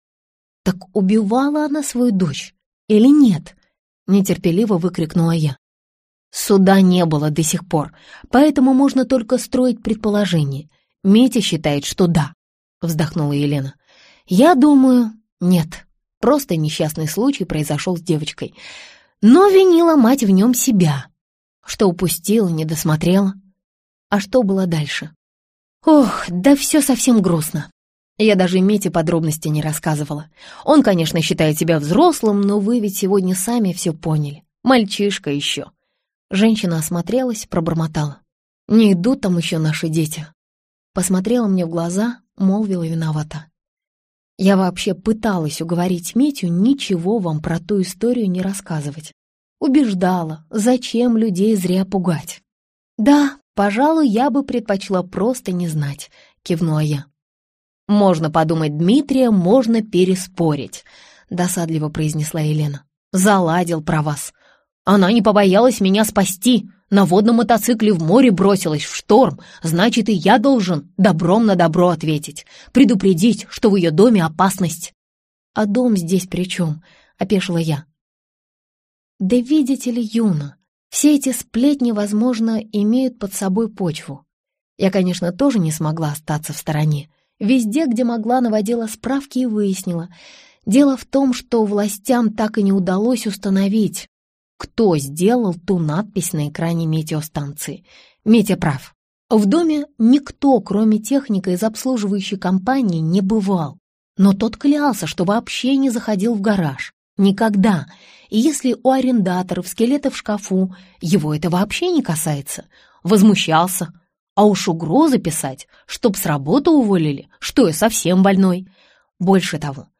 Аудиокнига Изгнание в рай | Библиотека аудиокниг